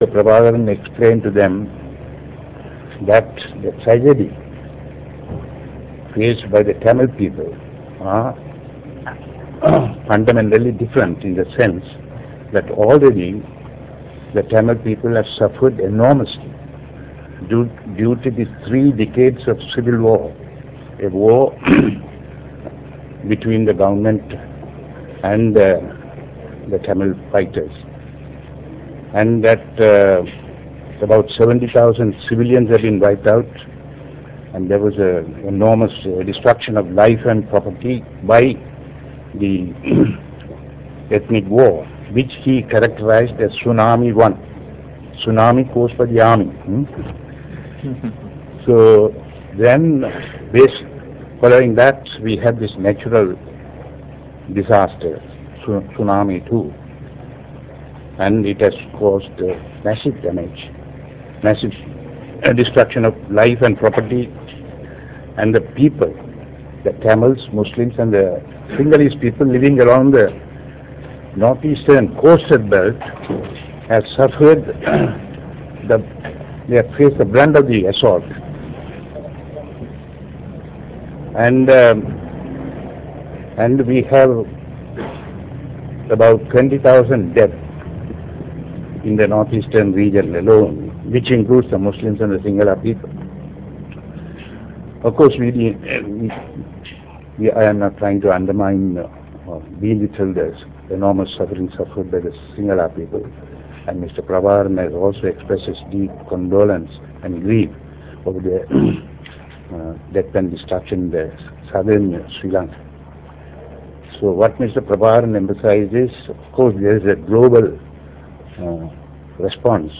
Real Audio Icon Press briefing
Later Mr.Anton Balasingham and Mr.Thamilchelvan held a press briefing at the headquarters of the LTTE Planning Secretariat.